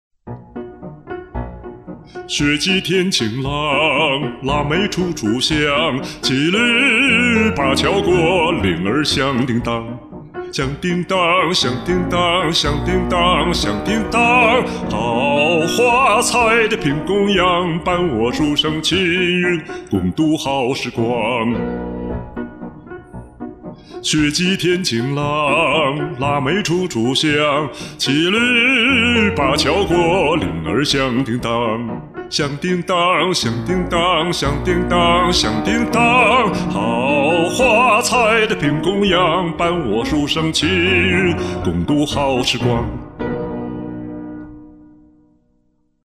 2. 《踏雪寻梅》这歌好像是男中音必唱的曲目，听过许多男中音唱过。